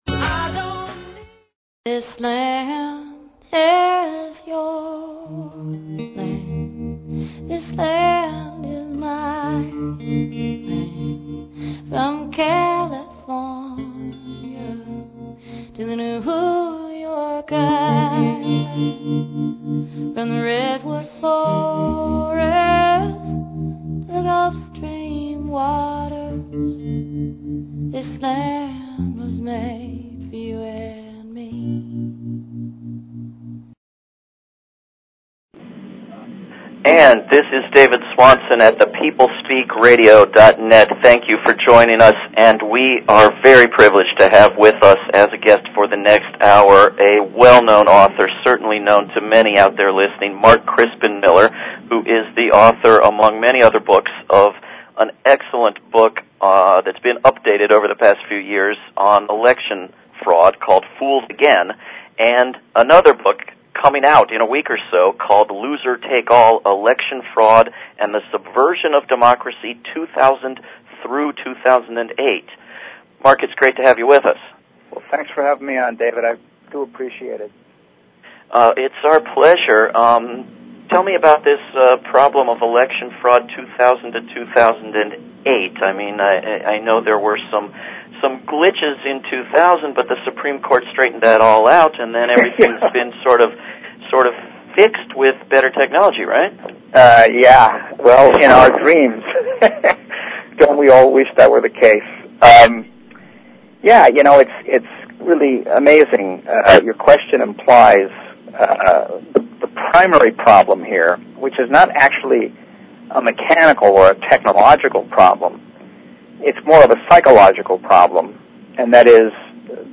Talk Show Episode, Audio Podcast, The_People_Speak and Courtesy of BBS Radio on , show guests , about , categorized as
Mark Crispin Miller Author/Professor